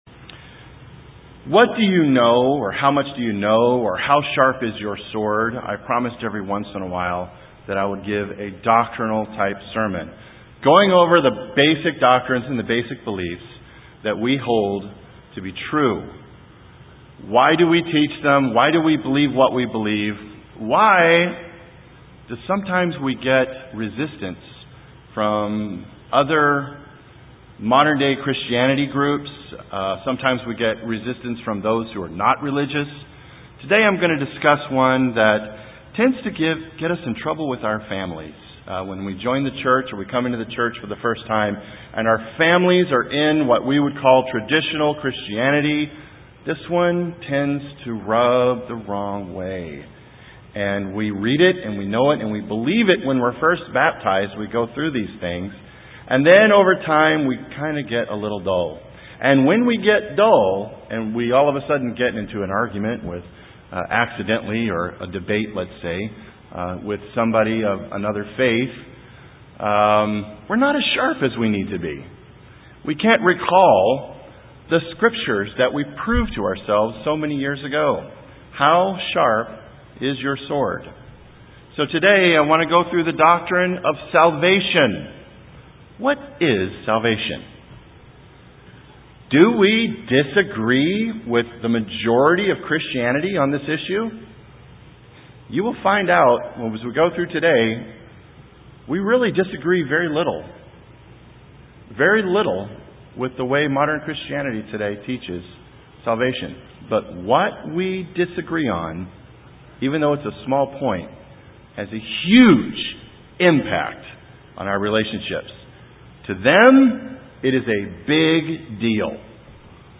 This sermon will help us to remember what we believe about what the bible says about salvation.